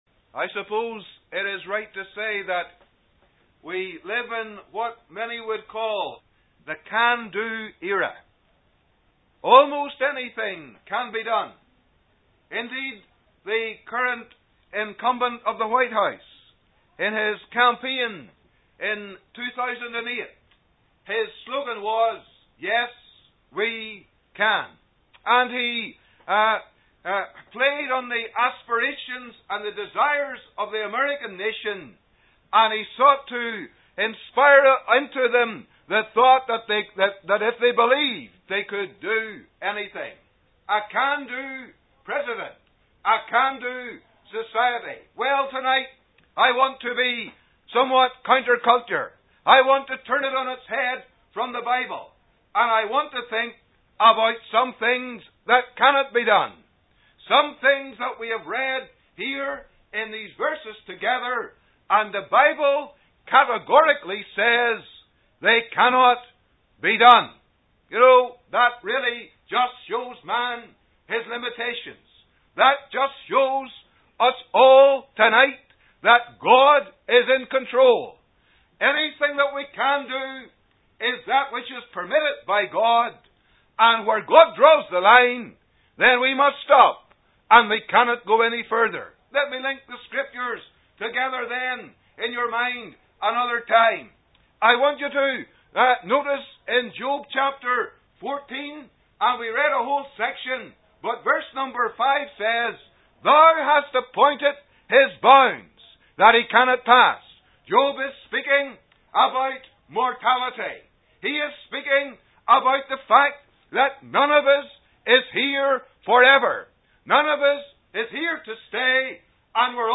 A date that cannot be changed (Job 14:5), a demand that cannot be challenged (John 3:3) and a divide that cannot be crossed (Luke 16:26). A solemn, powerful message (Message preached 19th July 2012)